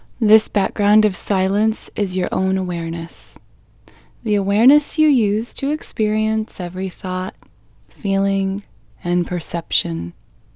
LOCATE IN English Female 10